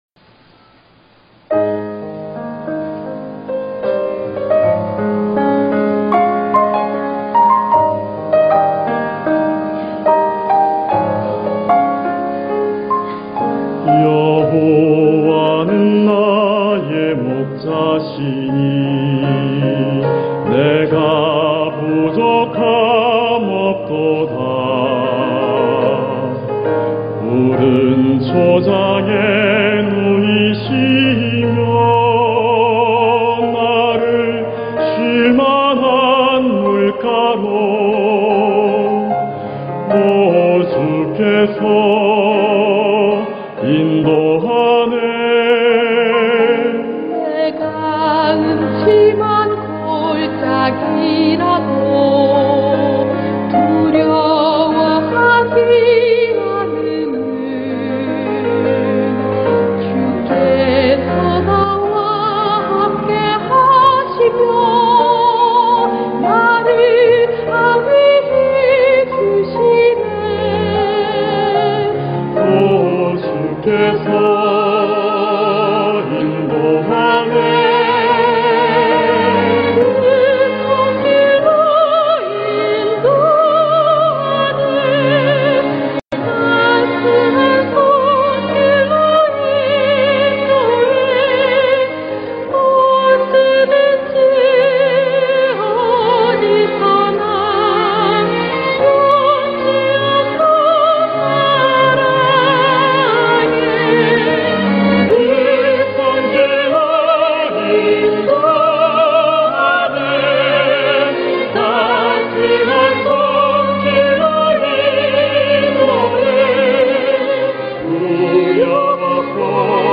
성가공연 여호와는 나의 목자시니 좋아요 즐겨찾기 프로그램 소개 프로그램 응원 공유 다운로드 오늘날까지도 많은 그리스도인들에게 사랑을 받으며 불리고 있는 성가들에는 하나님의 영감을 얻어 써내려간 그리스도인들의 간증, 그리고 세상에서 가장 큰 선물인 구원을 주신 하나님에 대한 찬양이 담겨 있다.